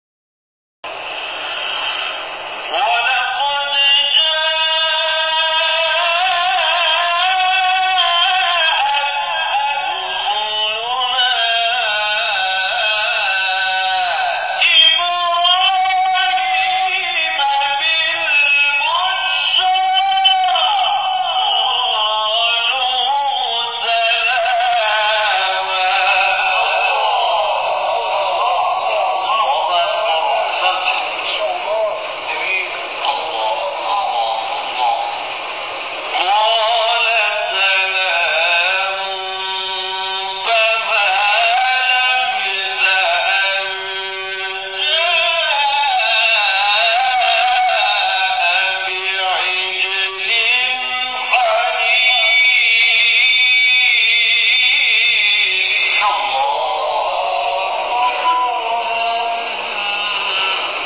شبکه اجتماعی: مقاطع صوتی از قاریان ممتاز کشور را می‌شنوید.
سوره هود در مقام رست